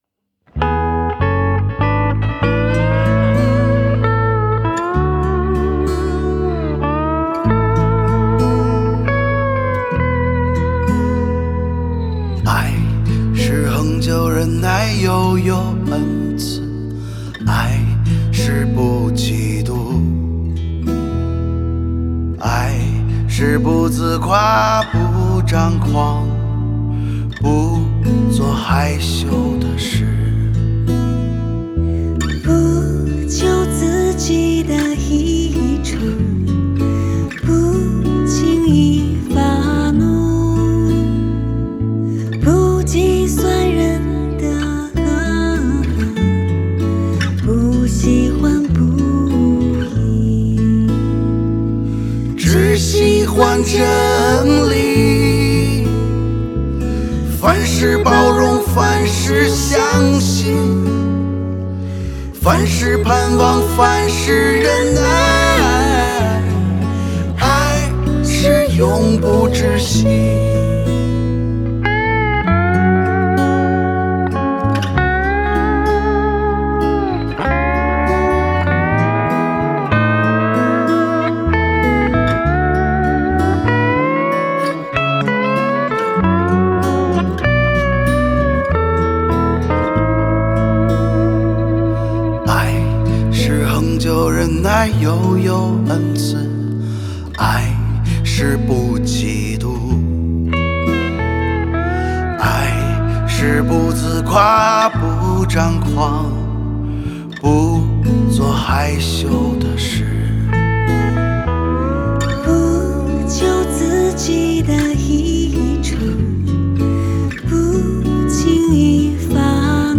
制作：Ekklesia国度12领导力 HAKA三层天祷告框架： 敬拜音乐：我们的神 默想经文及宣告： 当进入第四步，也用 1189 经文默想的方式，来选择 被圣灵感动要默想或者宣告的经文，来为五执华人国 际团队，来为家庭，来为自己进行经文的场景中默想，祷告，宣告，突破！